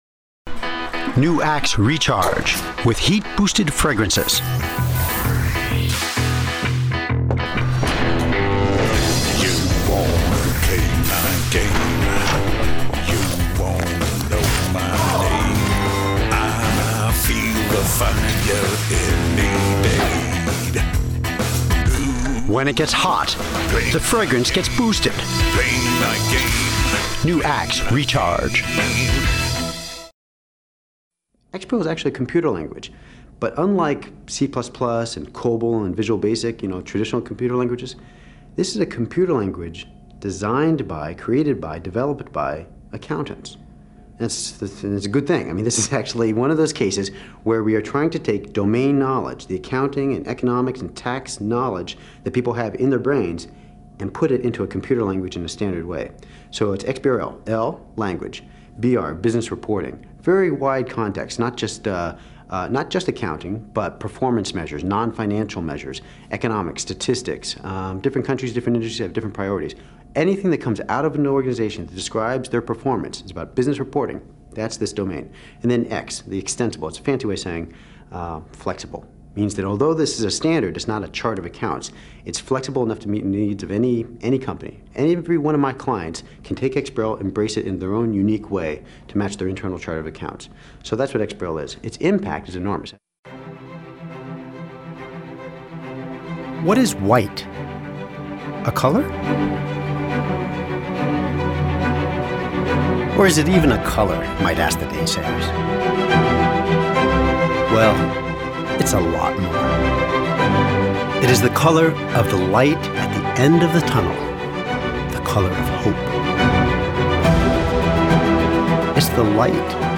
VOICE REEL
American Actor with great range.